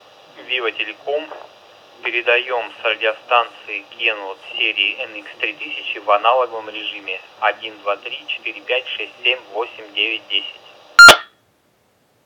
Пример модуляции (передачи) радиостанций серии NX-3000 в аналоговом режиме:
nx-3000-tx-analog.wav